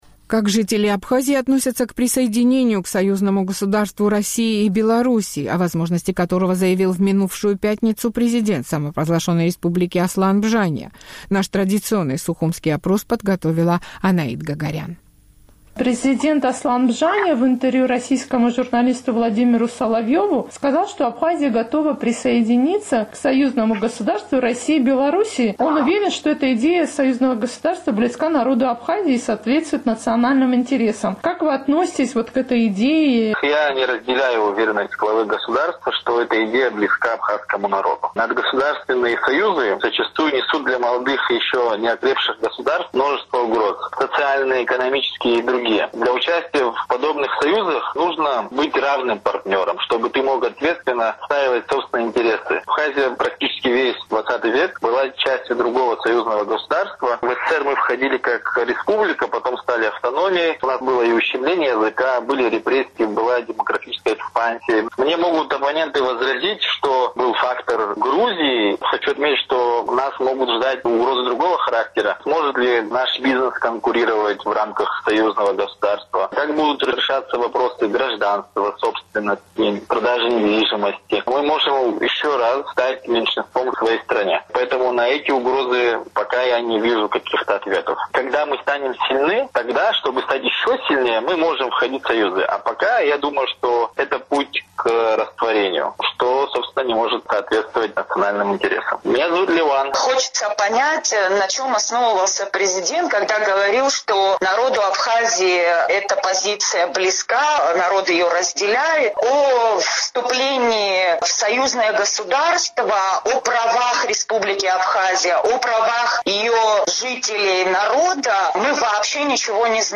Сухумский опрос – о присоединении Абхазии к союзному государству России и Беларуси